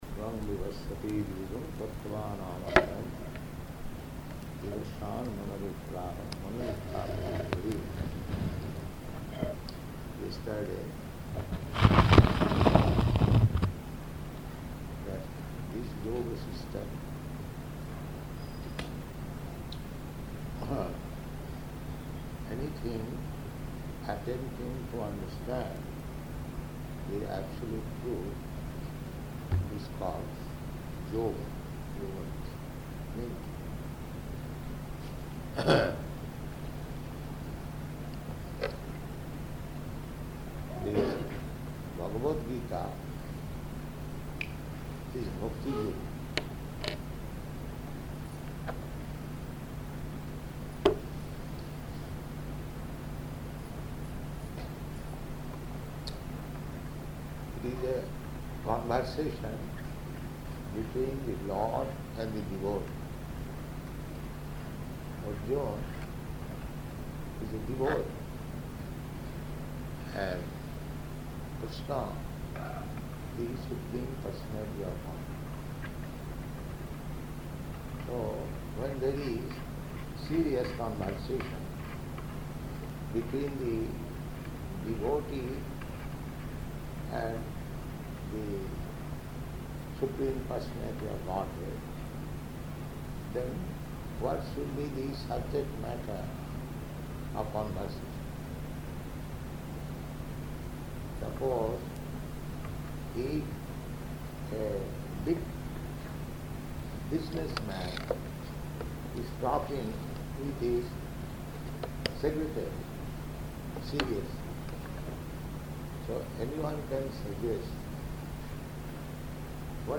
Location: Vṛndāvana
[poor recording]